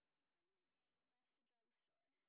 sp18_street_snr0.wav